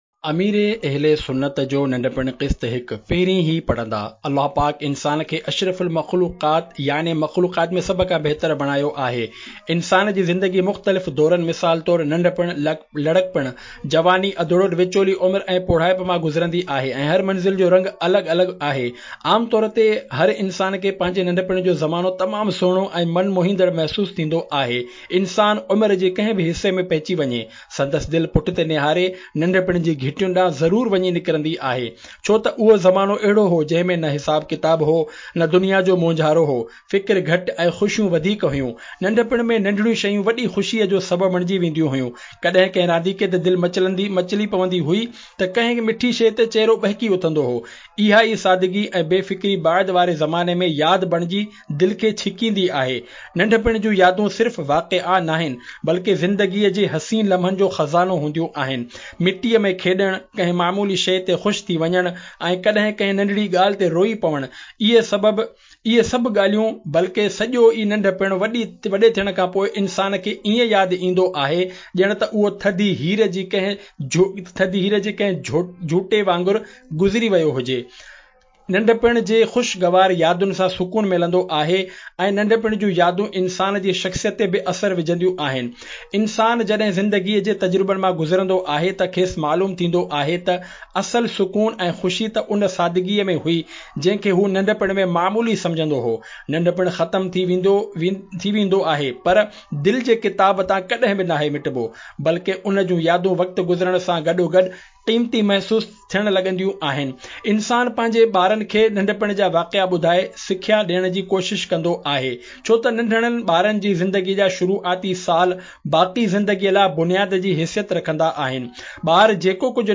Audiobok - Ameer e Ahl e Sunnat Ka Bachpan (Sindhi)